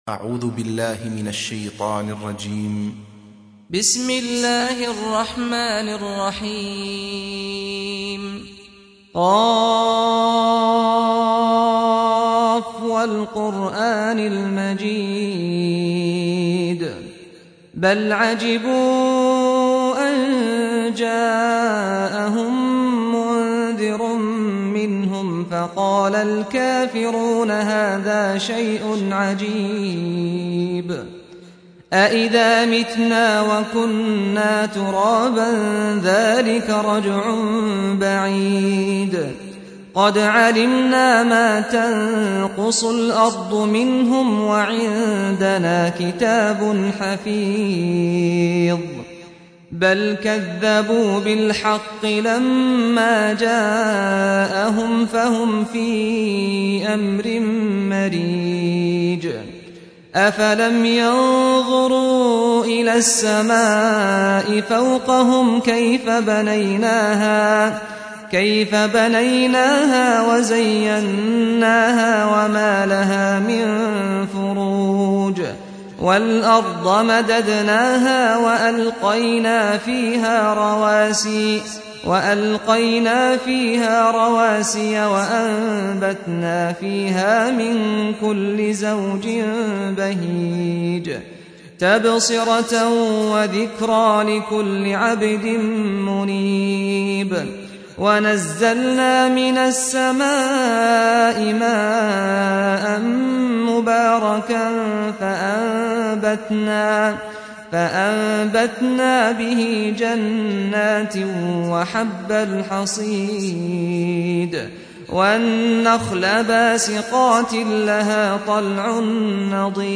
سُورَةُ ق بصوت الشيخ سعد الغامدي